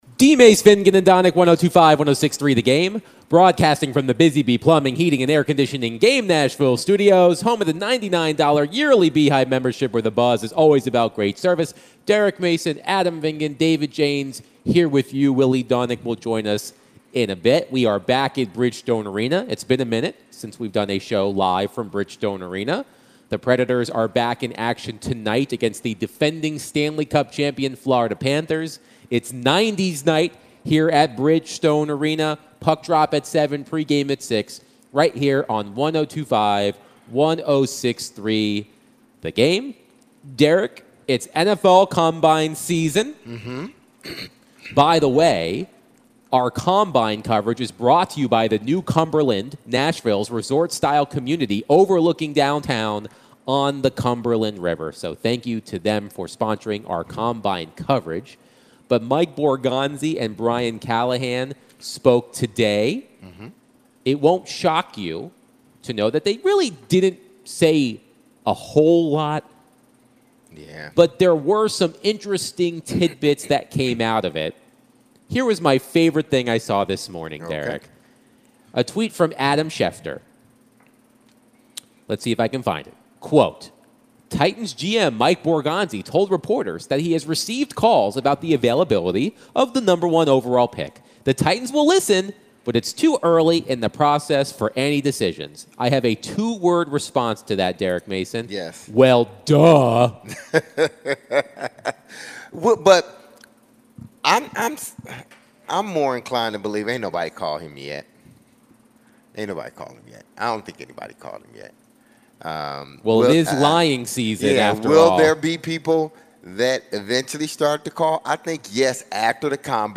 Should the Titans invest their number one overall draft pick in edge risher Abdul Carter? What do you think? the guys answered some calls and texts surrounding the Titans combine comments.